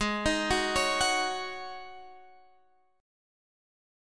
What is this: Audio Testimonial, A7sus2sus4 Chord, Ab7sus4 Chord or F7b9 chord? Ab7sus4 Chord